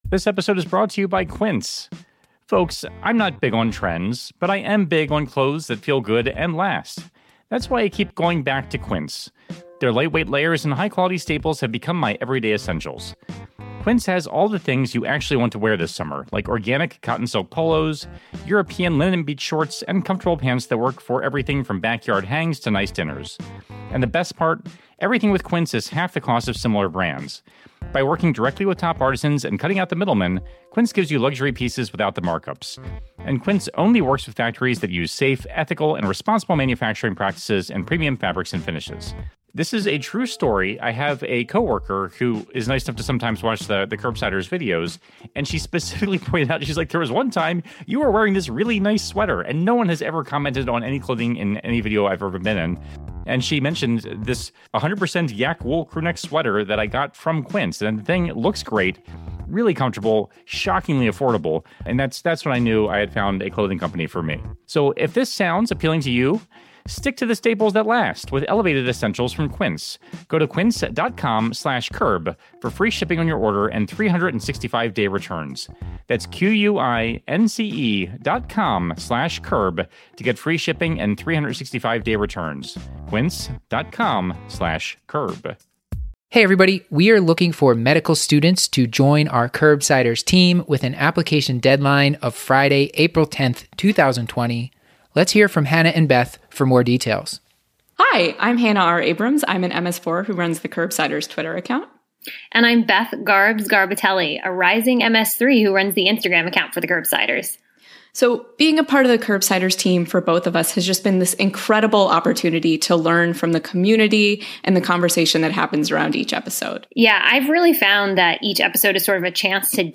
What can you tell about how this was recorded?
LIVE! from Mayo Clinic, a practical approach to lymphadenopathy